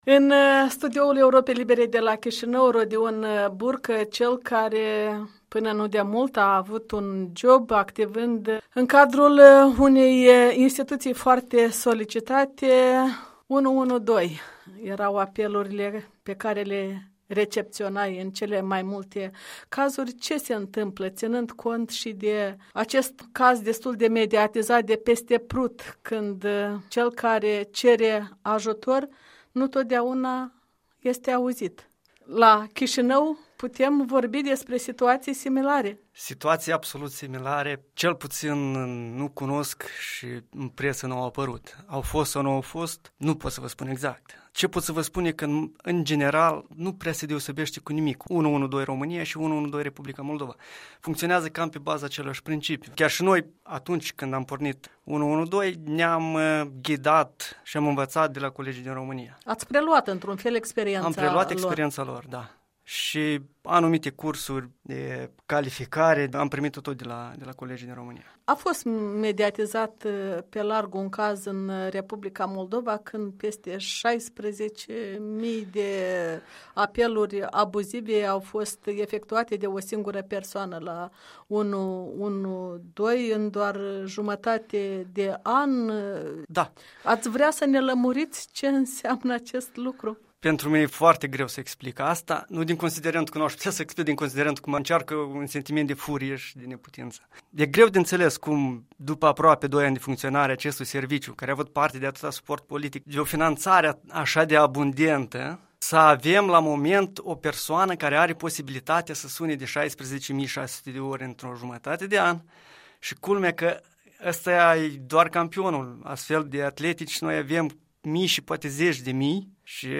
În dialog cu un fost dispecer al serviciului 112 de la Chișinău.